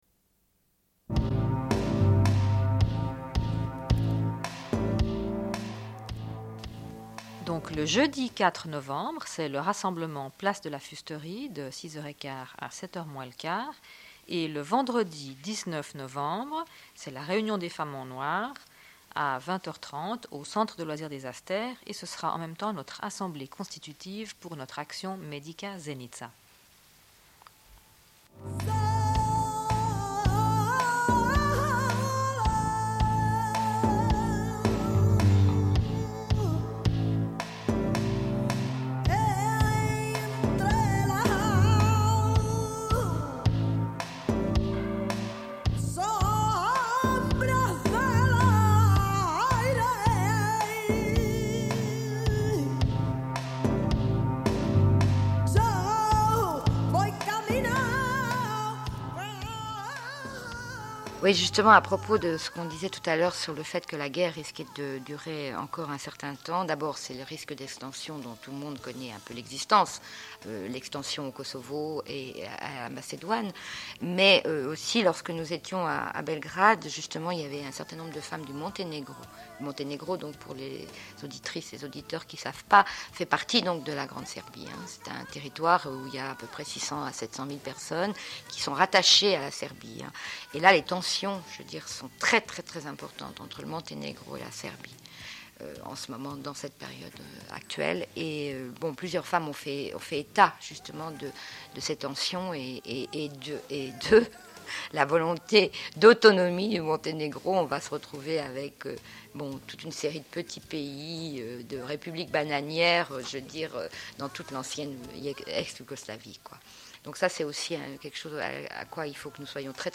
Bulletin d'information de Radio Pleine Lune du 27.10.1993 - Archives contestataires
Une cassette audio, face B31:27